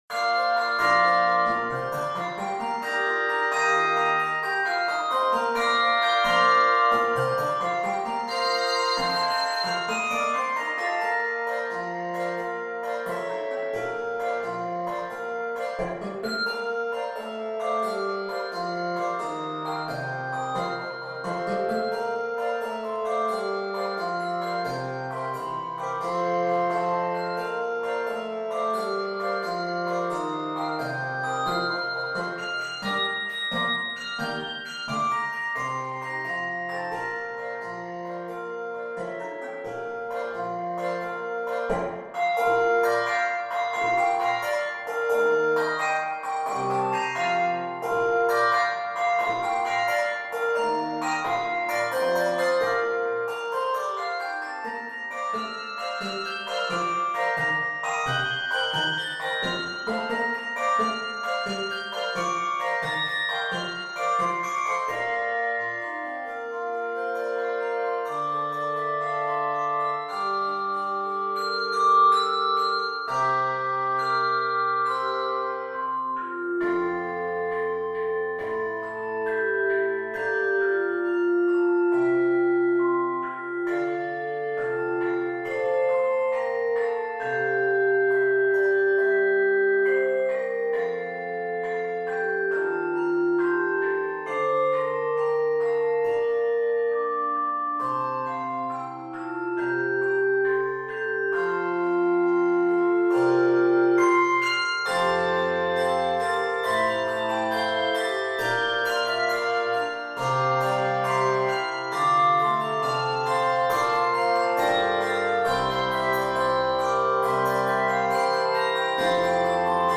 handbells
It features five Canadian folk songs